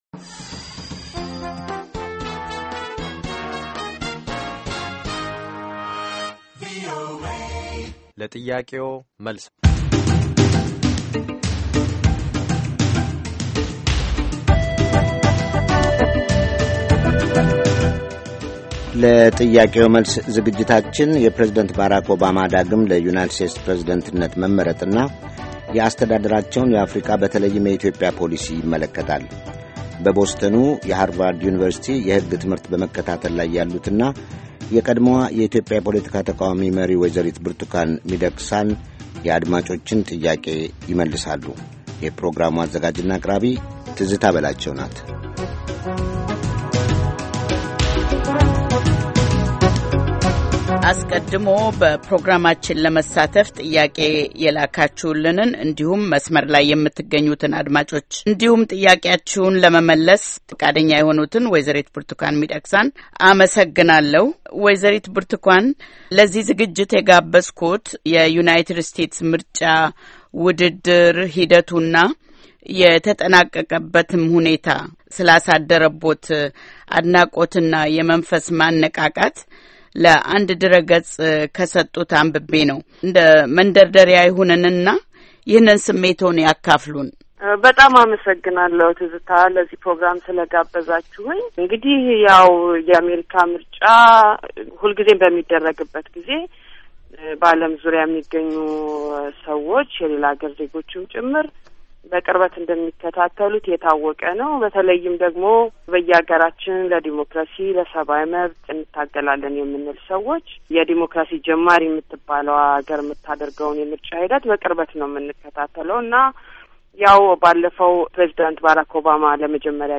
Call-in, Obama and Democracy in Africa, Birtukan Mideksa